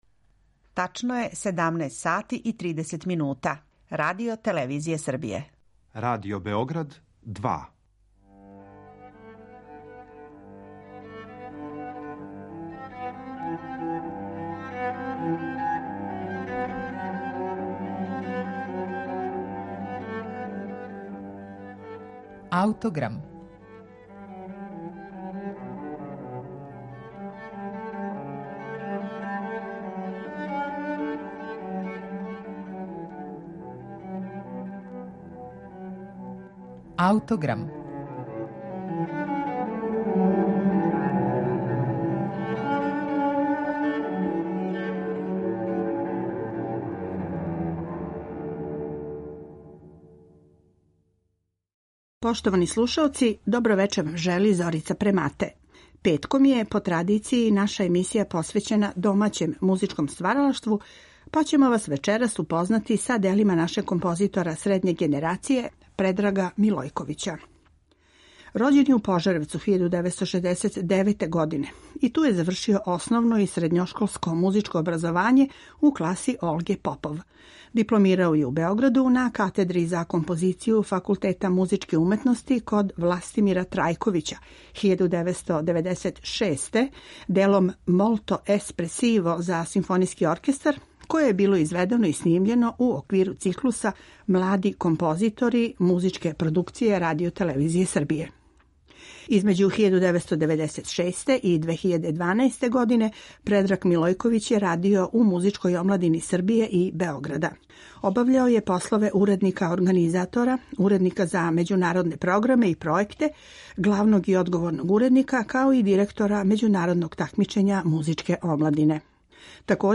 Овога пута емитоваћемо његово остварење „Dreamless" - „Без снова" за велики камерни ансамбл.